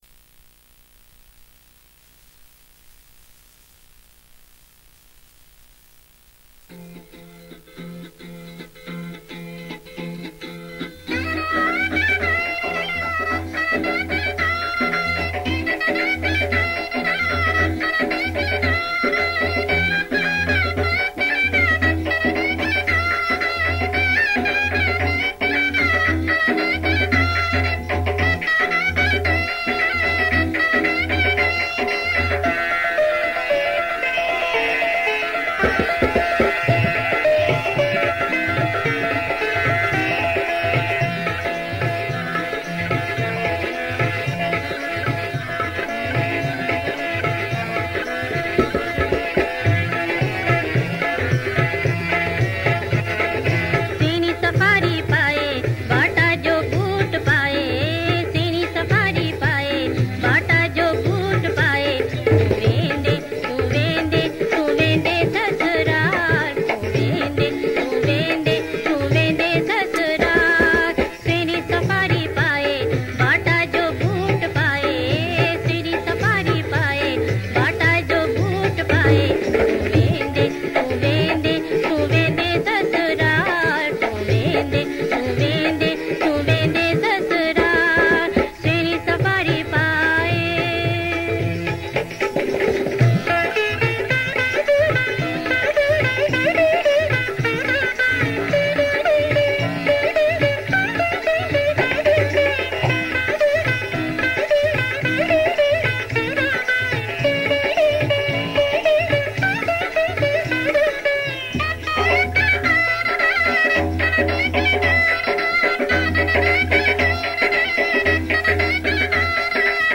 Sindhi Ladas and Marriage Mazaki Songs